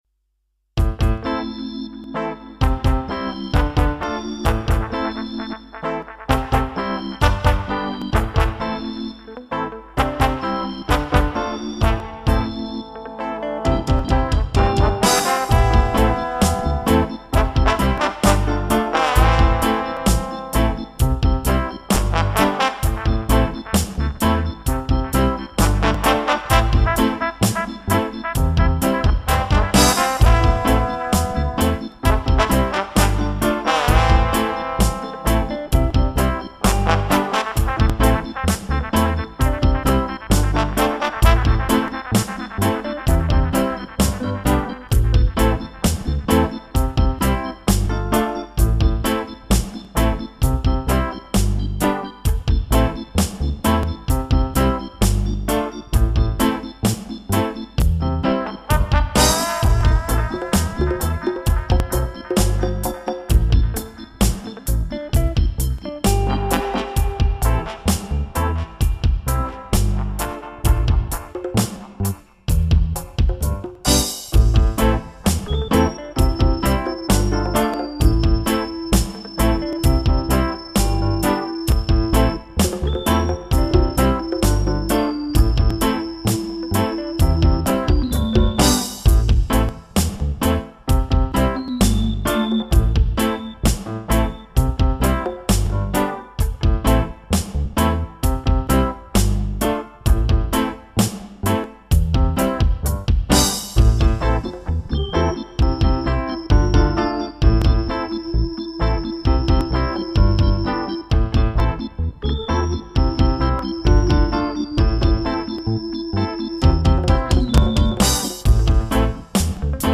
It's a riddim
piano and organ
drums
solo guitar
horn & bass, and me at rythmic guitar !